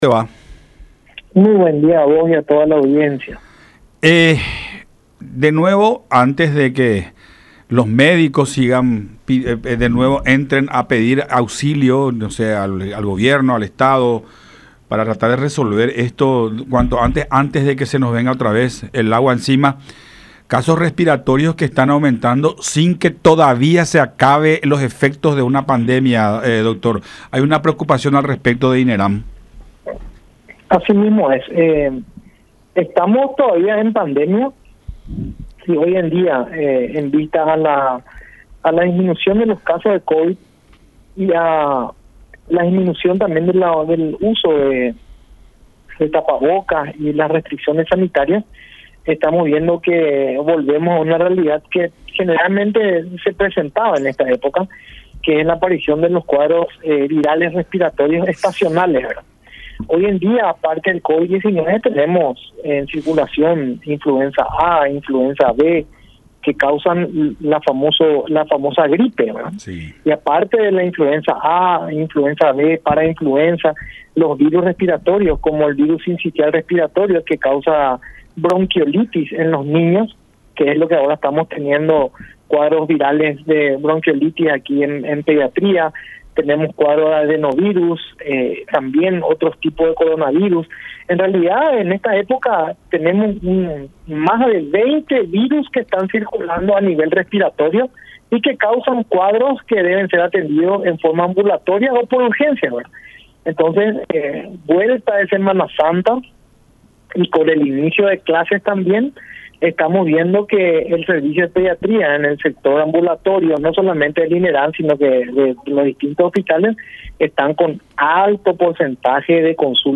en conversación con Todas Las Voces por La Unión.